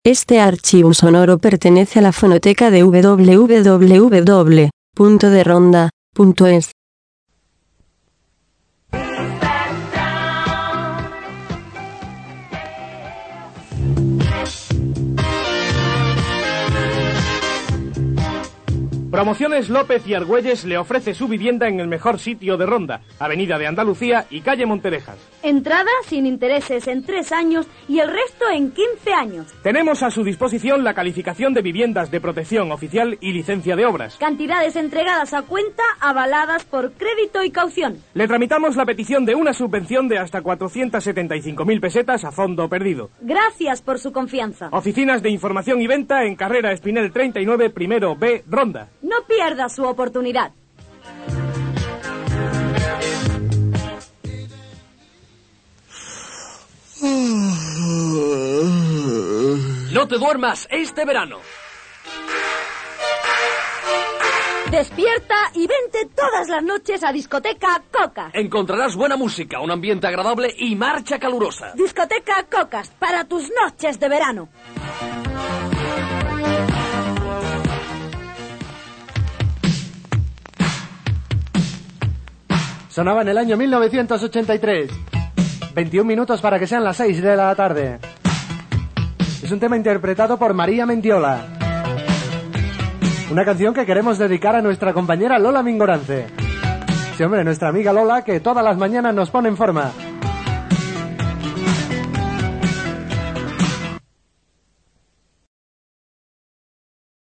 En el siguiente corte primero escucharemos un anuncio sobre la constructor a López y aArguelles